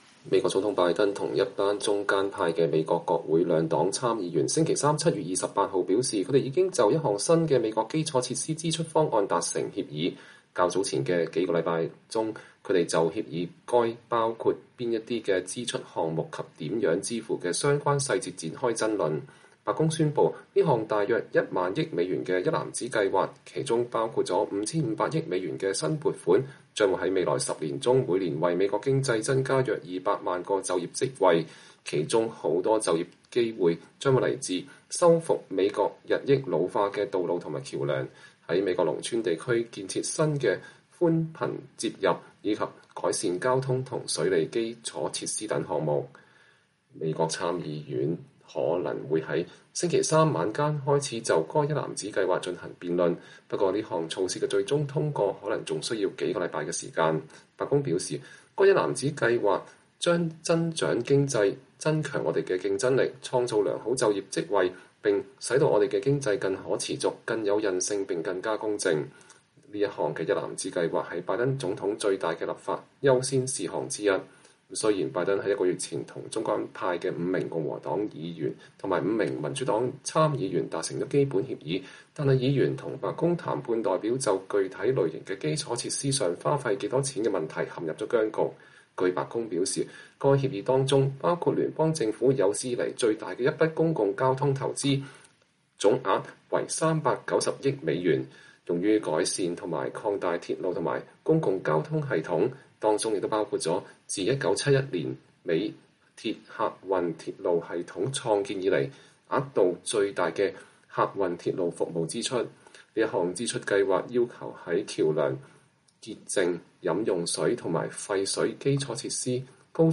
美國總統拜登在抵達賓夕法尼亞州阿倫敦市當地機場時向現場記者回答有關其基礎設施一攬子計劃的問題。